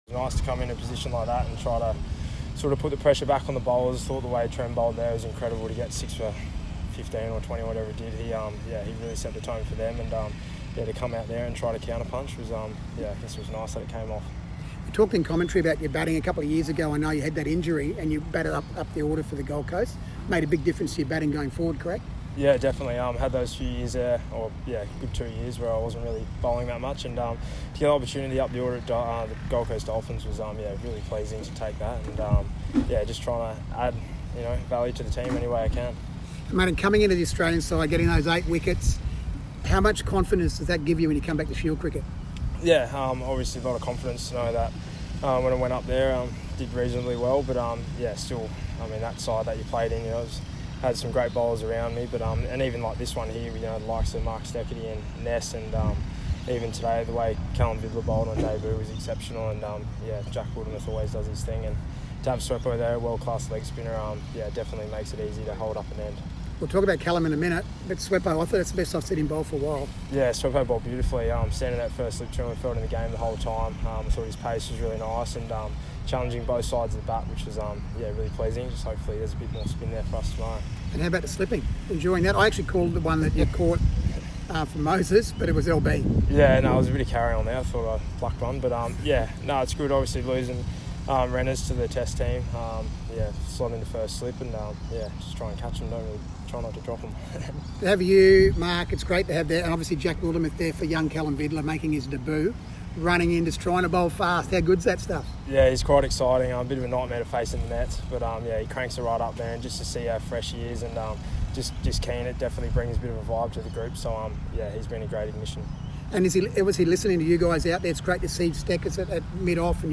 Xavier Bartlett Queensland who top scored with 70 in Qld’s innings Post-match interview (CA)